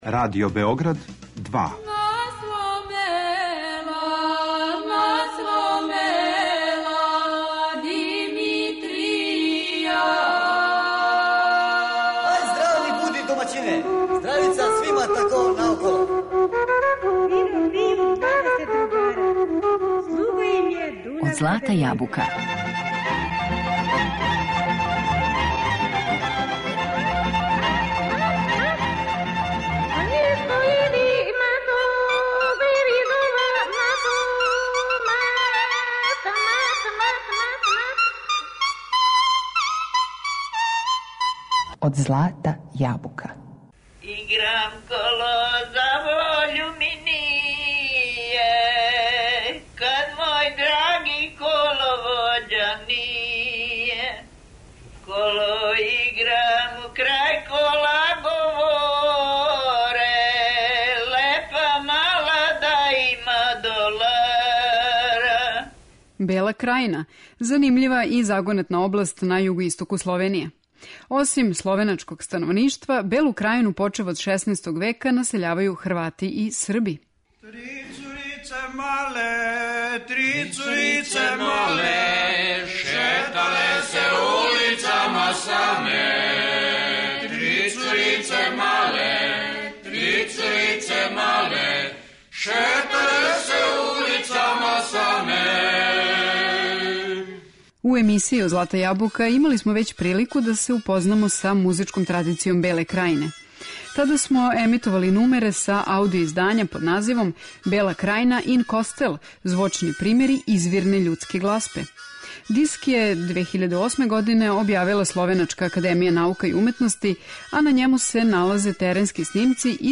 Реч је о певачком наслеђу ускока у југоисточној словеначкој области. Слушаћемо музику српског и хрватског становништва, које води порекло од ускока досељаваних на овај простор почев од 16. века.